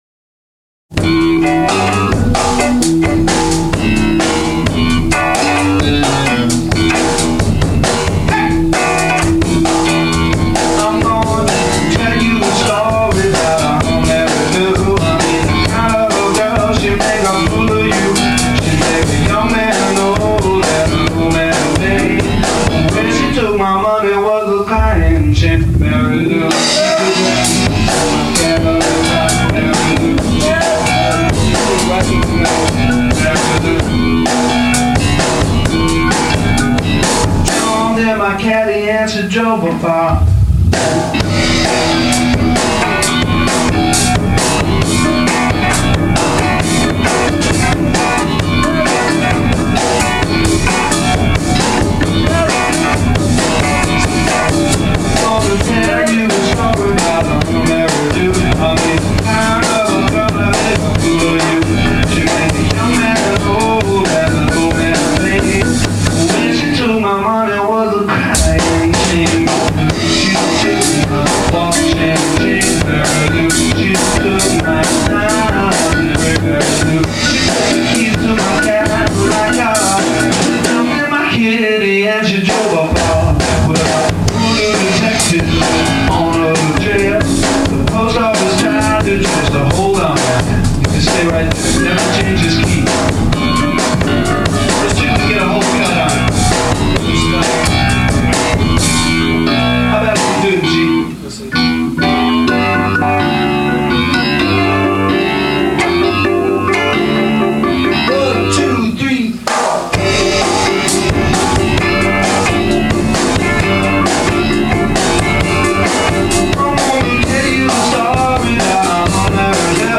Genre : Rock
Studio Rehearsal, January 19, 1973